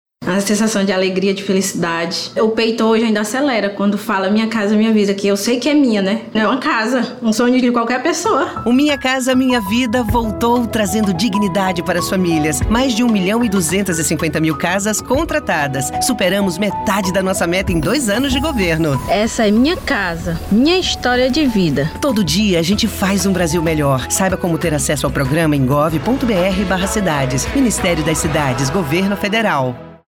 Spots Spot Governo Federal - Ações contra a dengue - Regional - ES O Espírito Santo está entre os seis estados com mais chance de surto da dengue em 2025.